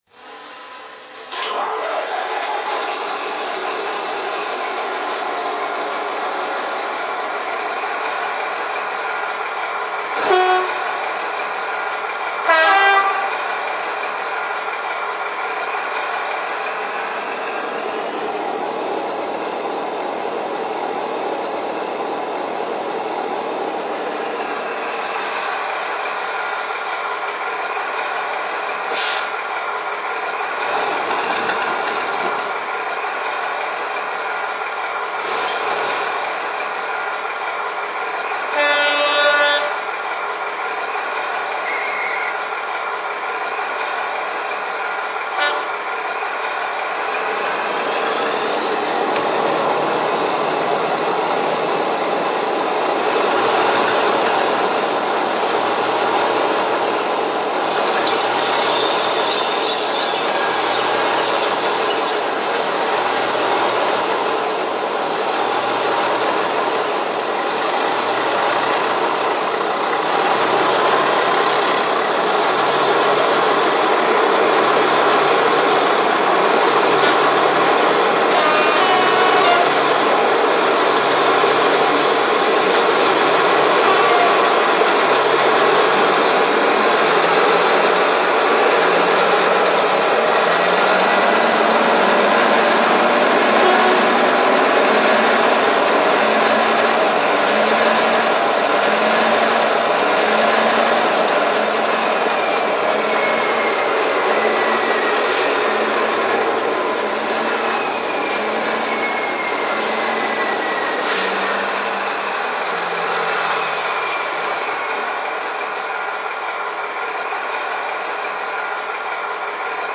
Creators of authentic British digital model railway sounds for Zimo DCC decoders
Class 14 Diesel Shunter
Prime Mover: Paxman Ventura 6YJXL, hydraulic transmission
Some sounds in a project may be 'generic', but the key parts of a project are always genuine sounds from the correct locomotive, unless otherwise stated.